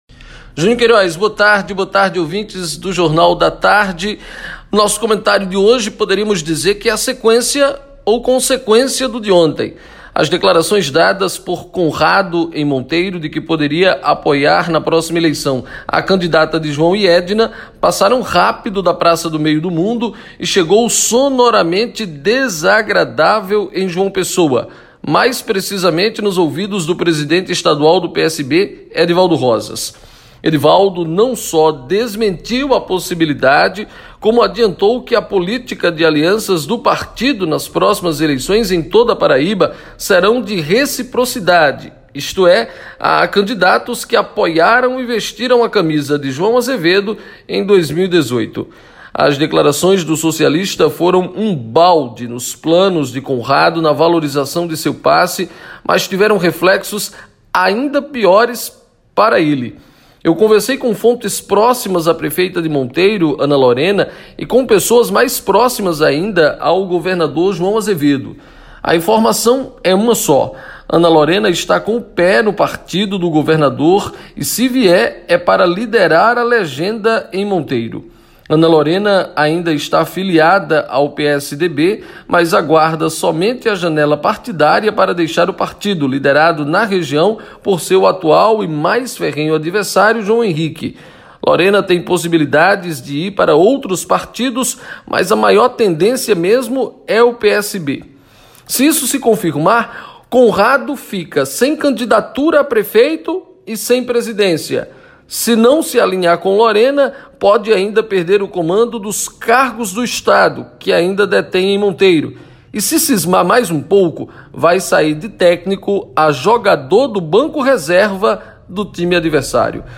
comentário completo